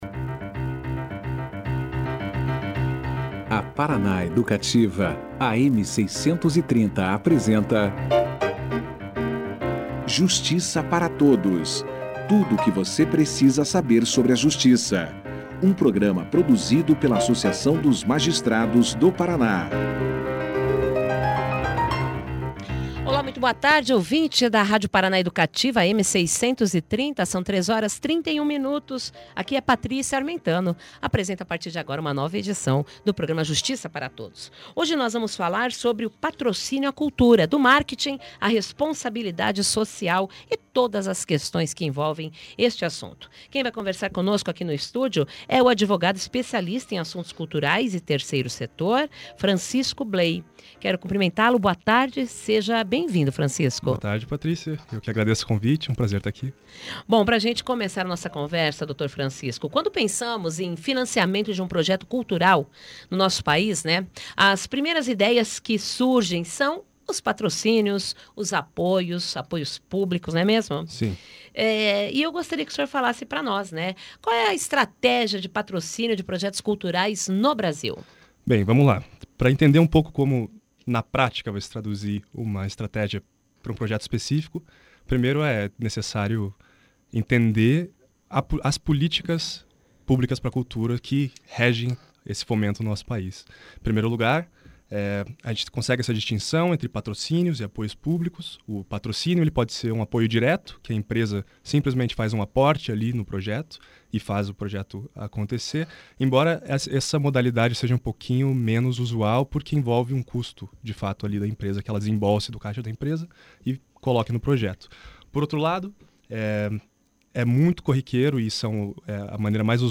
>>Clique Aqui e Confira a Entrevista na Integra<<